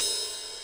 Crashes & Cymbals
CYMBAL18.WAV